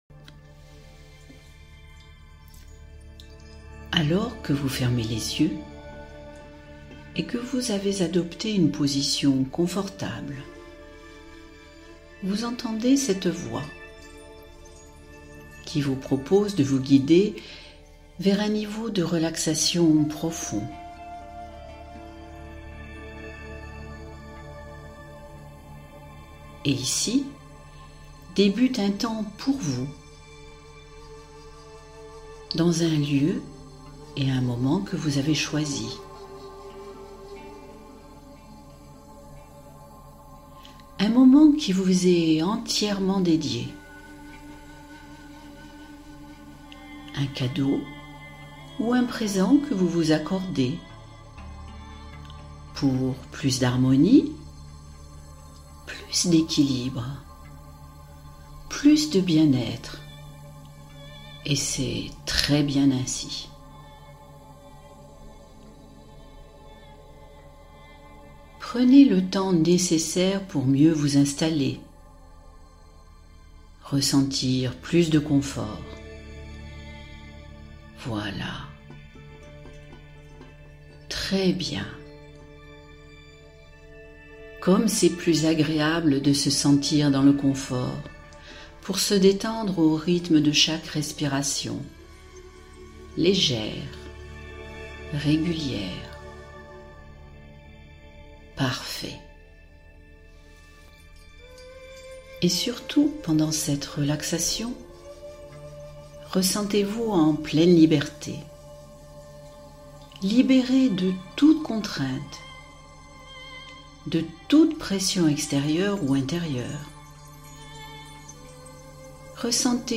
Désamorcer la phobie sociale — Hypnose douce et progressive